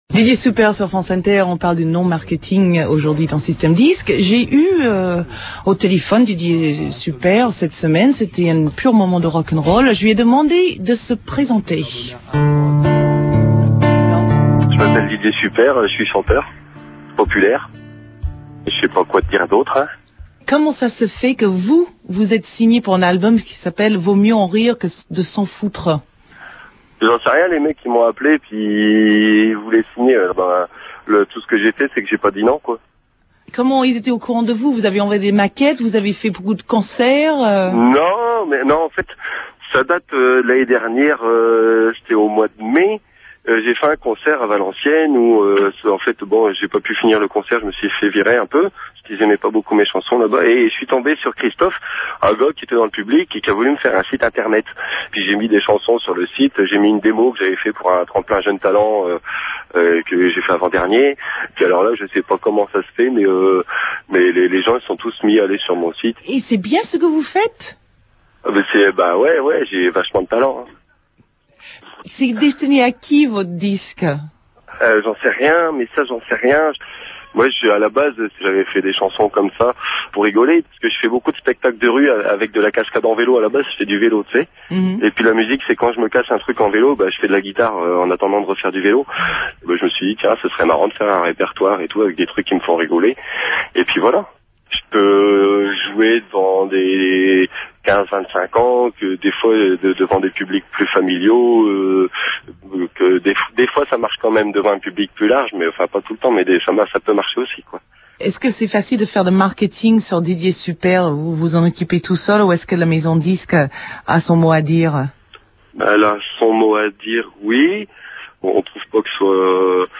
interview-didier+.mp3